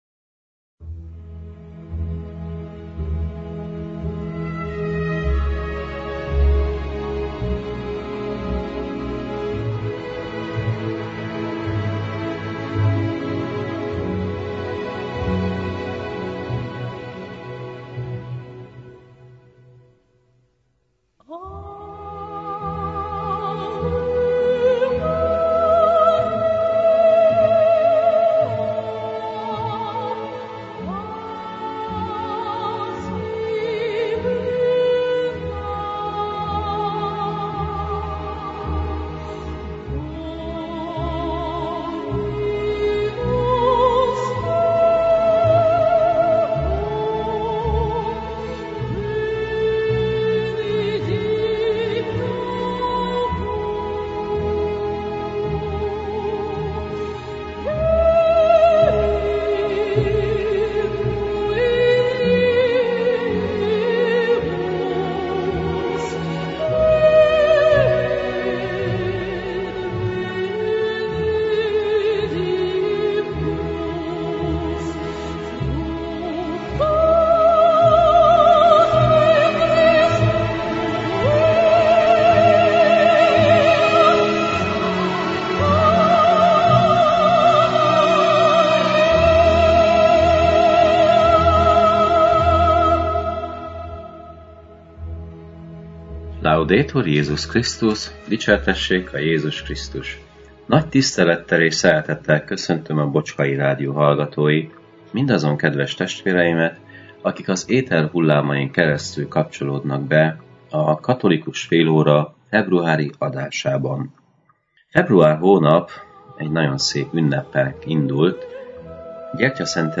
a clevelandi Szent Imre Katolikus Templomból.